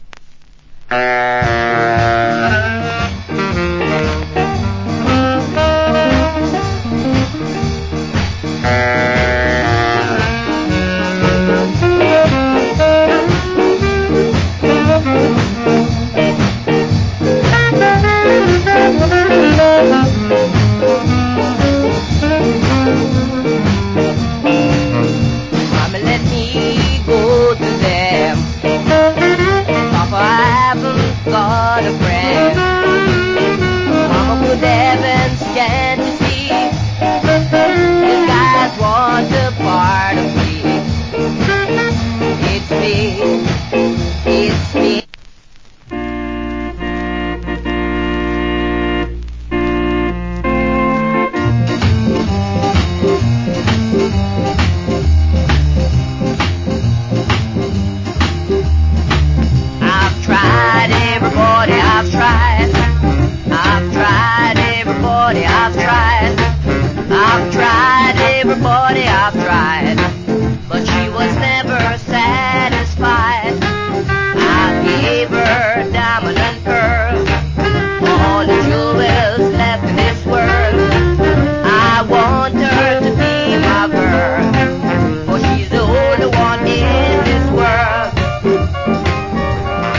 Wicked JA R&B.